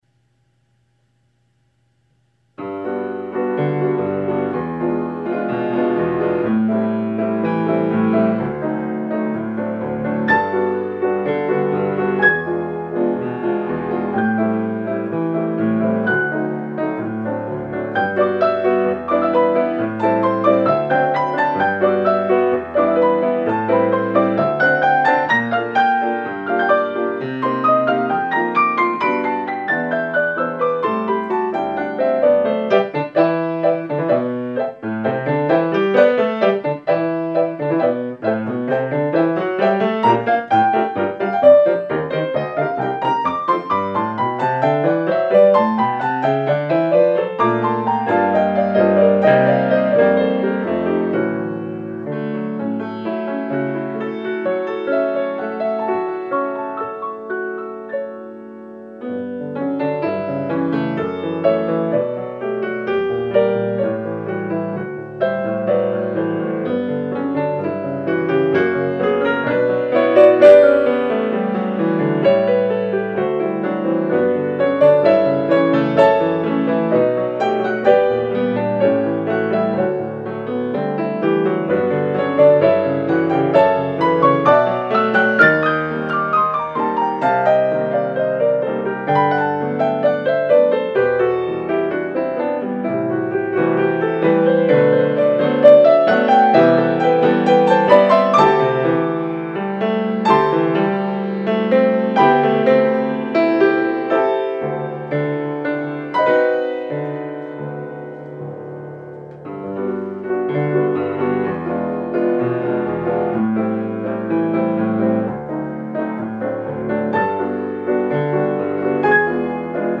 Piano Level: Intermediate
piano ensemble piece